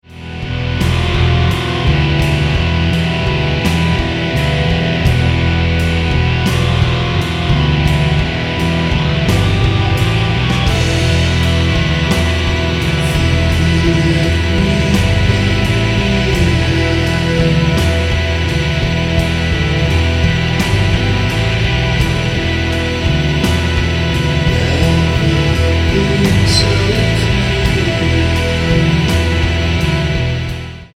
Dark rawk for doobious times.
rhythm guitar, lead guitar, percussion
bass guitar, keys, voices
drums